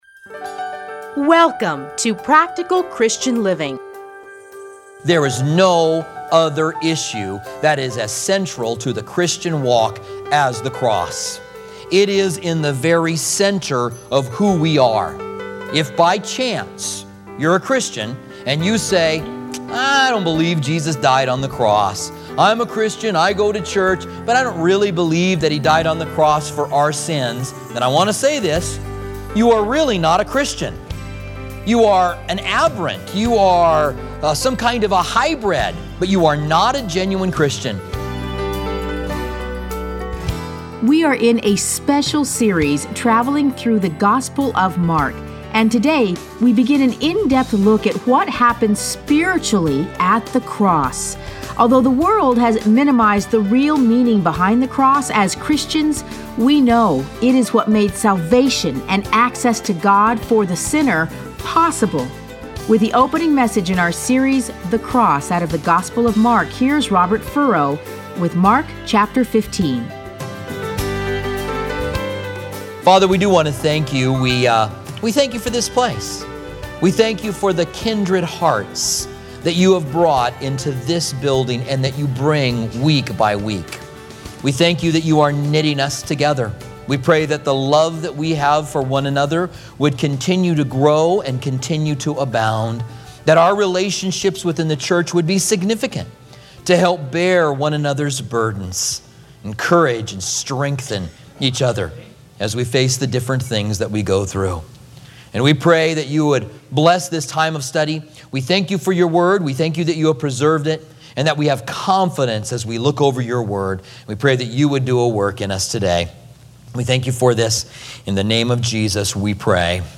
Listen to a teaching from Mark 15:20.